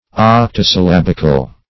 Search Result for " octosyllabical" : The Collaborative International Dictionary of English v.0.48: Octosyllabic \Oc`to*syl*lab"ic\, Octosyllabical \Oc`to*syl*lab"ic*al\, a. [L. octosyllabus.
octosyllabical.mp3